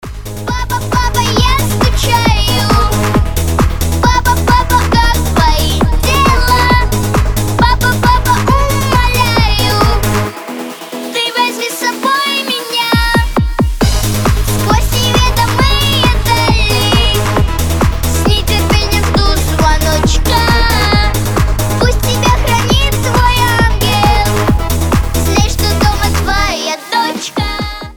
Танцевальные рингтоны
Детский голос
Клубные , Поп